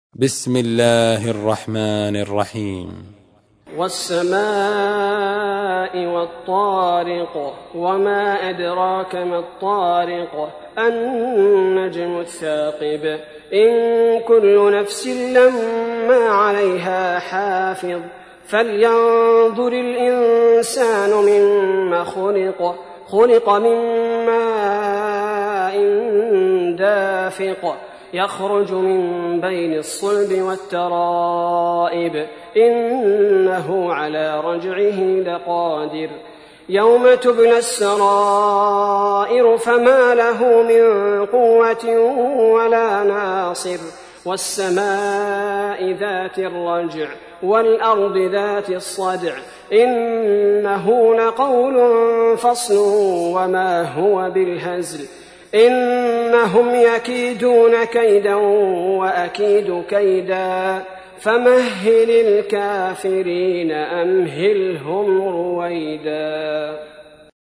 تحميل : 86. سورة الطارق / القارئ عبد البارئ الثبيتي / القرآن الكريم / موقع يا حسين